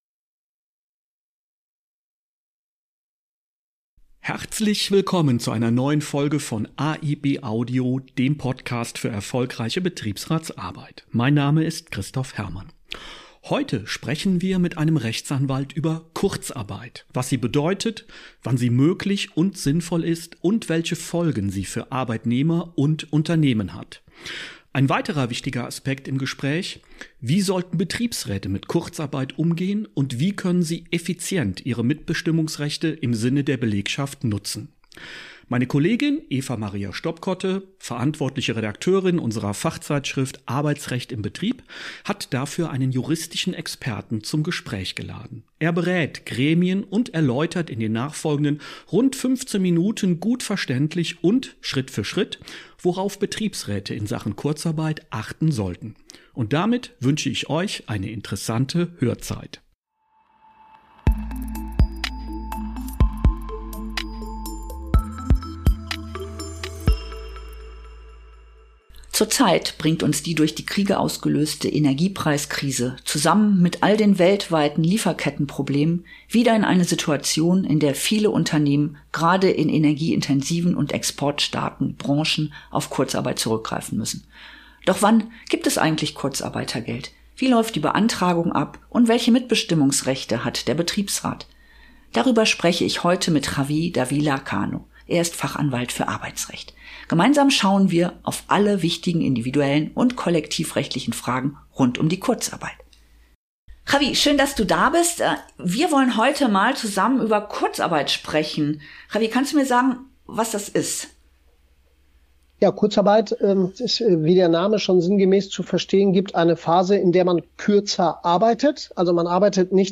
In dieser Folge sprechen wir mit einem Fachanwalt für Arbeitsrecht über Kurzarbeit: Was sie bedeutet, wann sie sinnvoll ist und welche Folgen sie für Beschäftigte und Unternehmen hat.